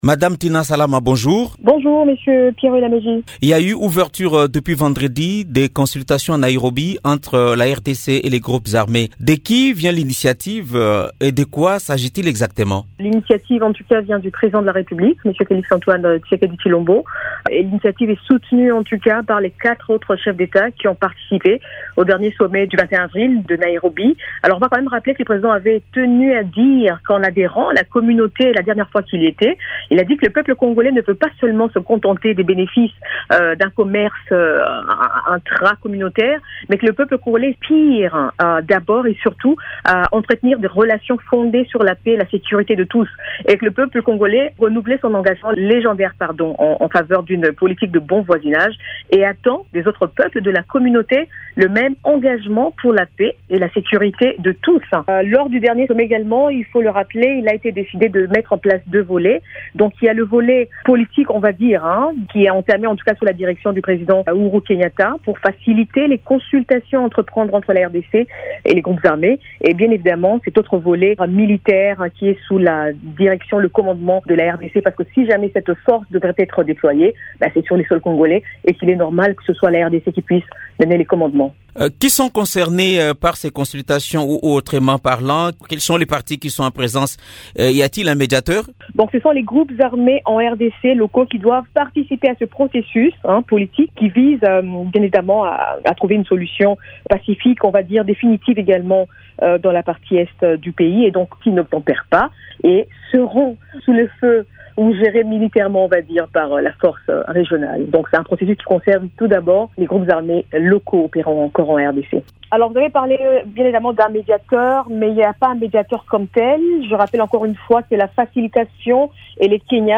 Elle évoque, dans un entretien à Radio Okapi dimanche 24 avril,  des consultations entamées le vendredi  22 avril à Nairobi au Kenya entre les émissaires de la Présidence congolaise et les groupes armés,  dans le cadre du processus de pacification de l’Est de la RDC.